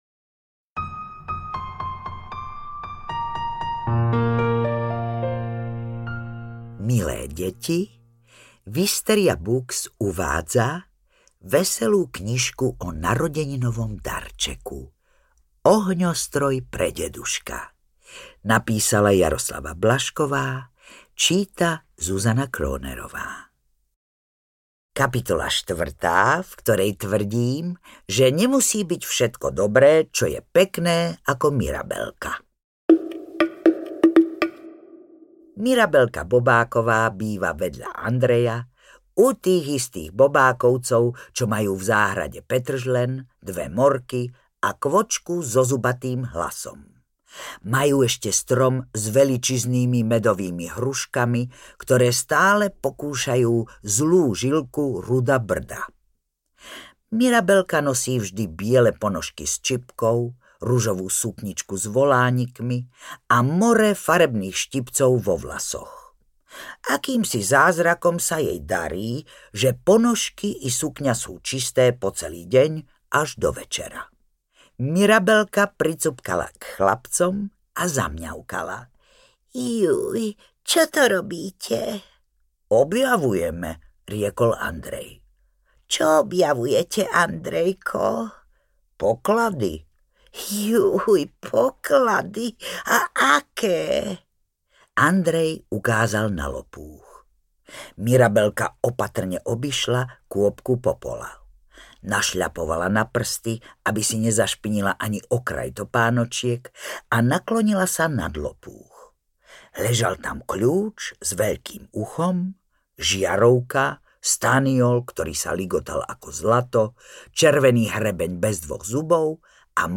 Ohňostroj pre deduška audiokniha
Ukázka z knihy
• InterpretZuzana Kronerová